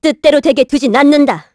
Hilda-Vox_Skill1_kr.wav